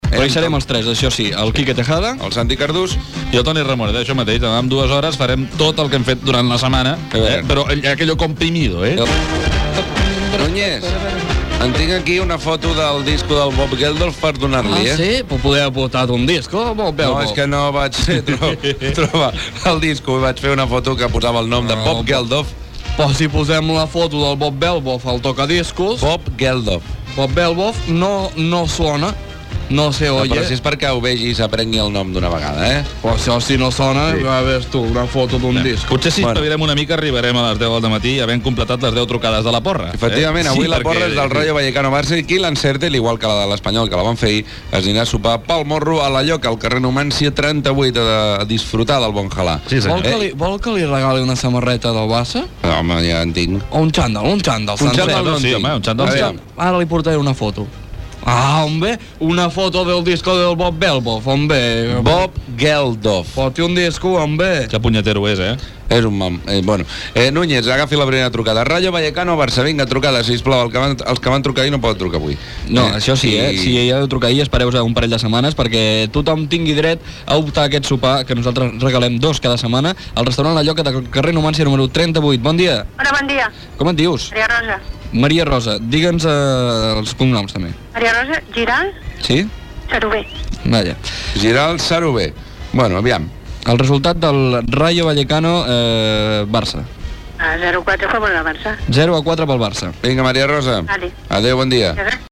Entreteniment
FM